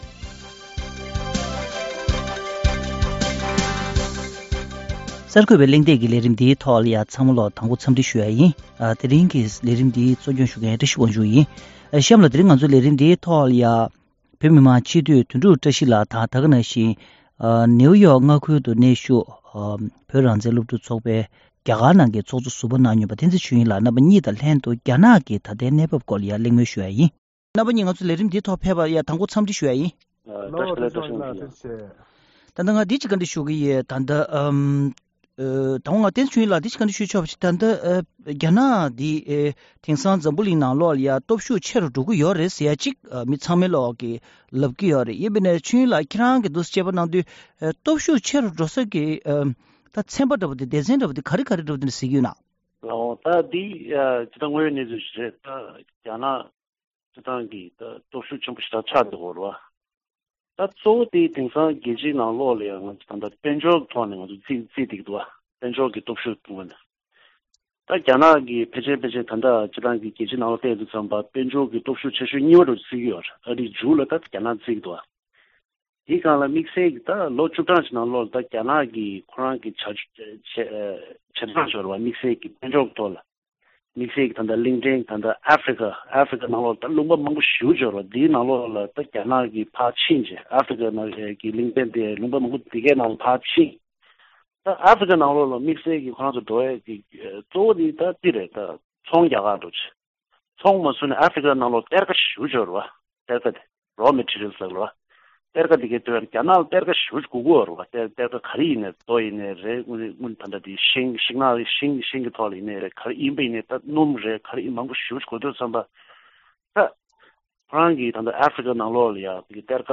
འཛམ་གླིང་ནང་དུ་རྒྱ་ནག་གི་ནུས་སྟོབས་ཞེས་པའི་བརྗོད་གཞིའི་ཐོག་འབྲེལ་ཡོད་མི་སྣ་གཉིས་དང་གླེང་མོལ་ཞུས་པ།